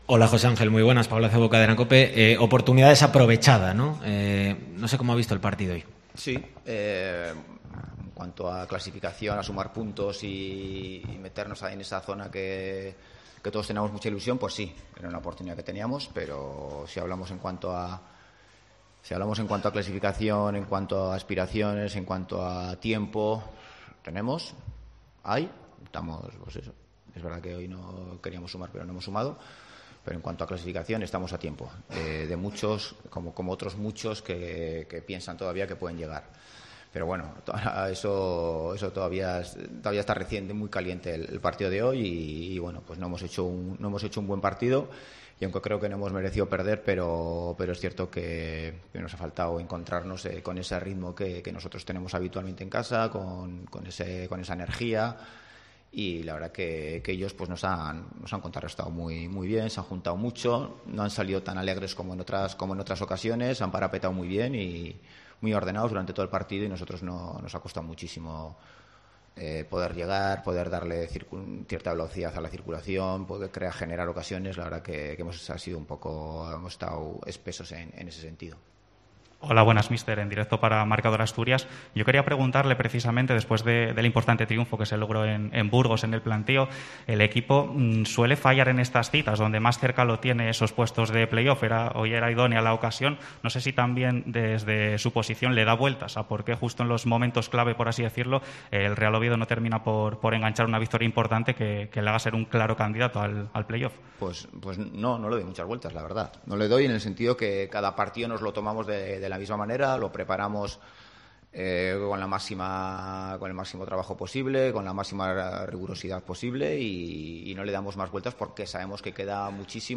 Rueda de prensa Ziganda (post Real Sociedad B)